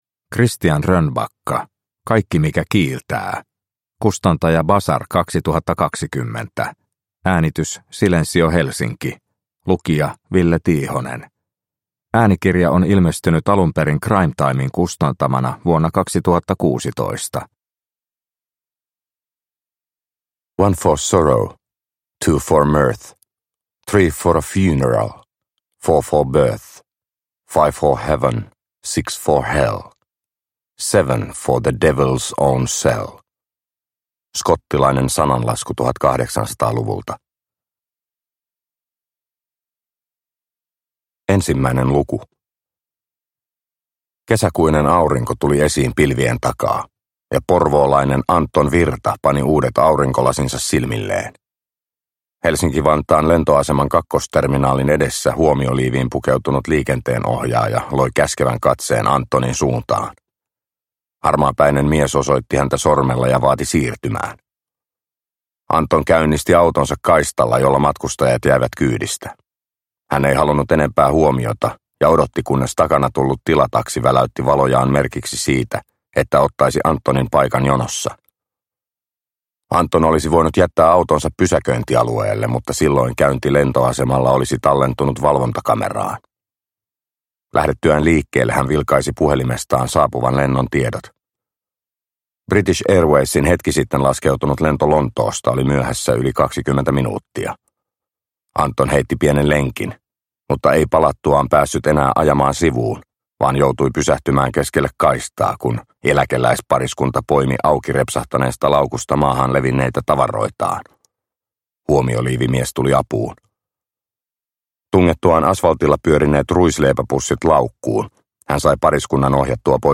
Kaikki mikä kiiltää – Ljudbok – Laddas ner